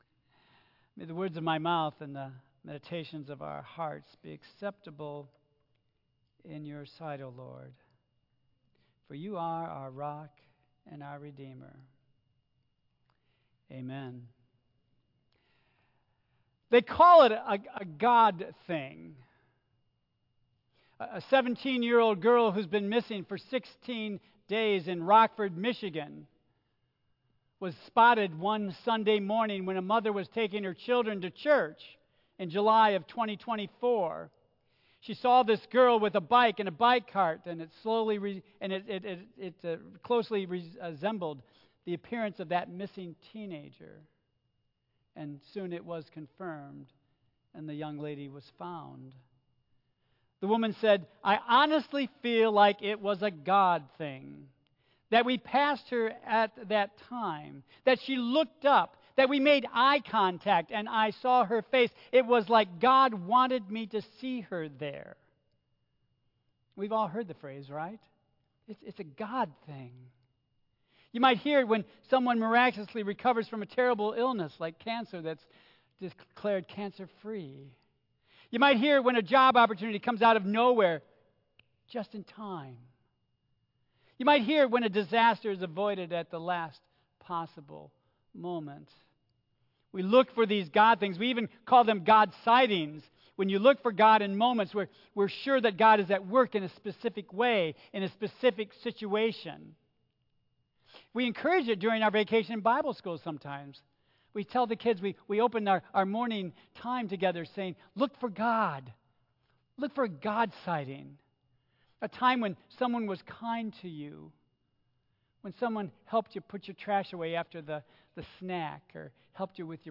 Tagged with Michigan , Sermon , Waterford Central United Methodist Church , Worship Audio (MP3) 8 MB Previous Making Cents of Shrewdness Next We Gather...To Recognize Our Common Needs